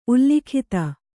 ♪ ullikhita